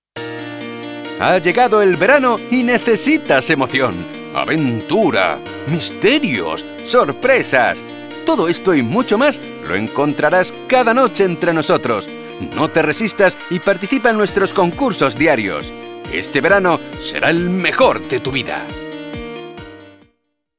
Neuman U87ai TlAudio 5051 Focusrite soundcard Protols 12,8,3
Sprechprobe: Sonstiges (Muttersprache):
Dubbing actor with 26 years of experience.